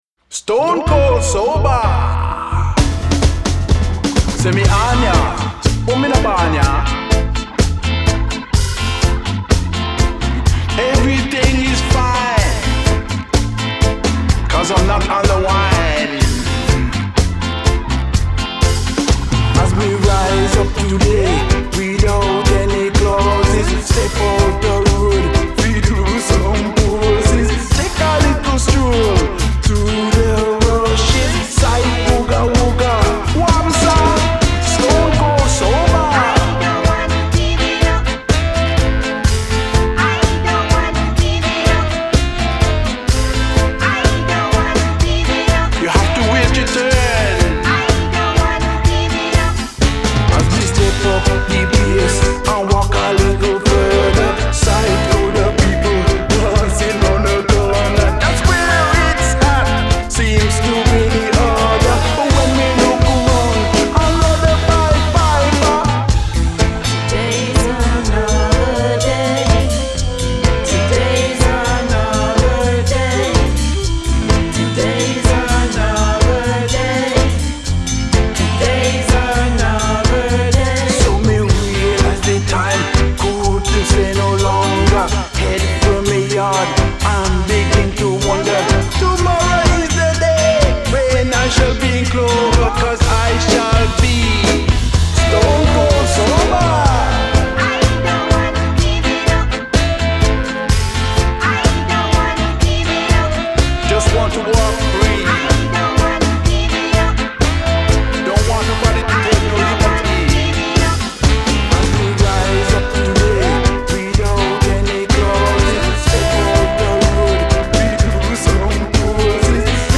Genre: Ska, Reggae, Dub